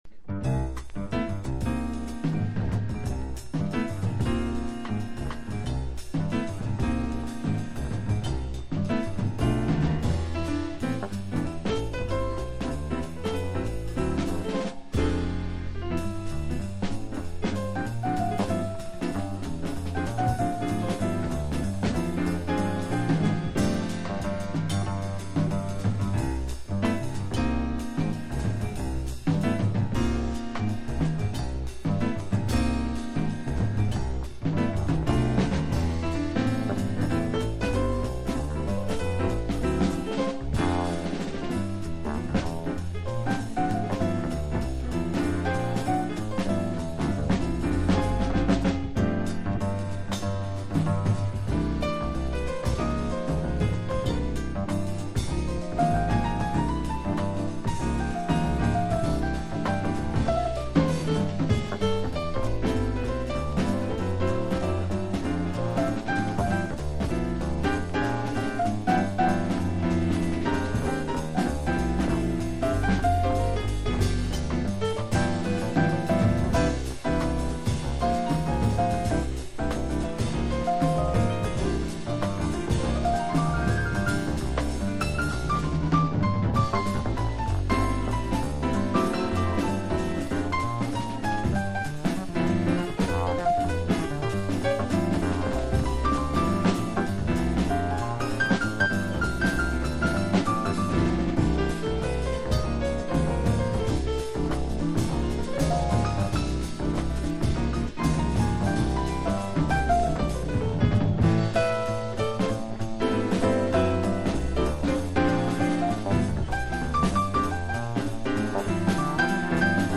トリオもの名盤